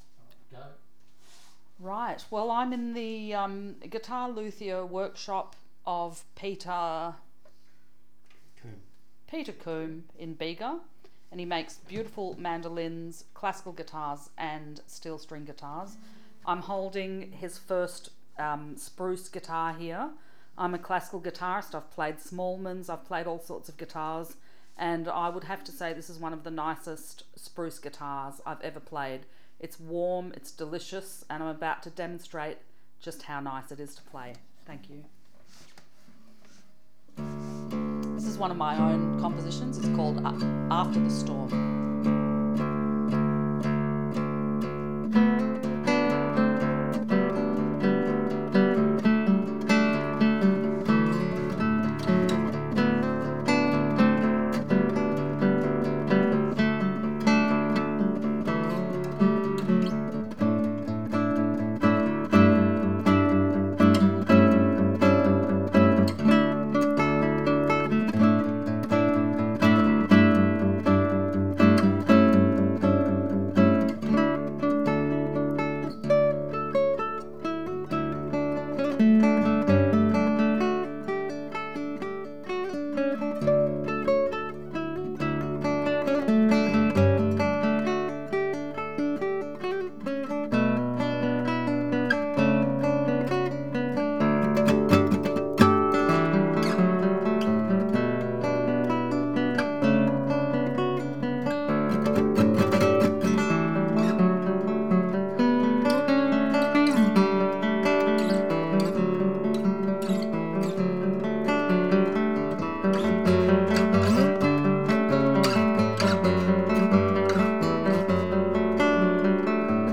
Classical Guitar
classical guitar